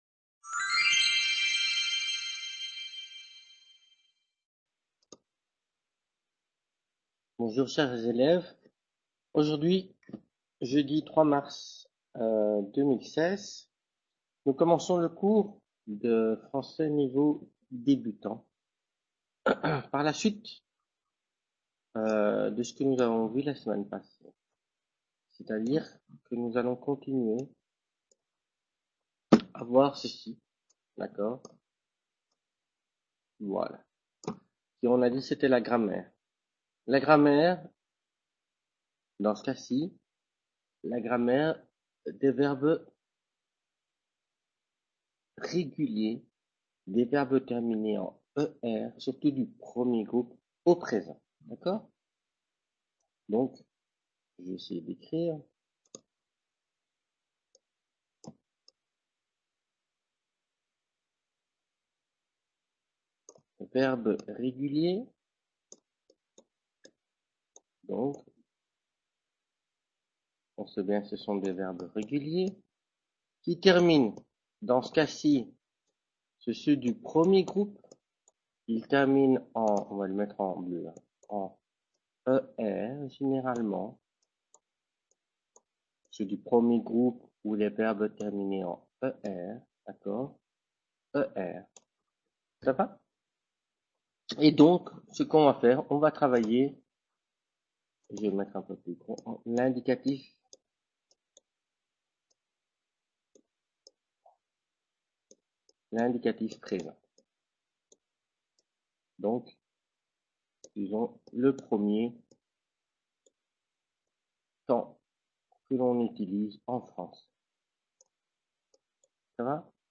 Clase de Francés Nivel Debutante 3/03/2016 | Repositorio Digital